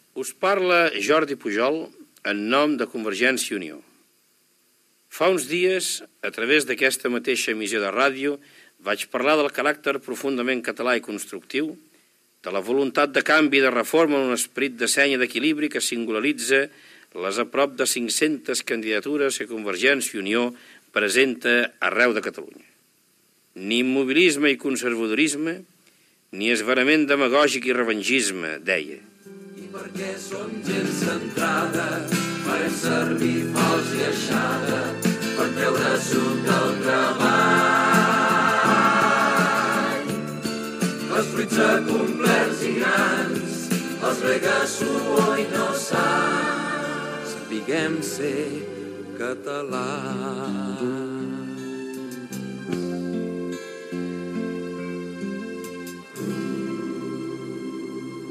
Espais gratuïts de propaganda electoral
Paraules de Jordi Pujol, de Covergència i Unió, a la campanya de les Eleccions Municipals (celebrades el 3 d'abril del 1979)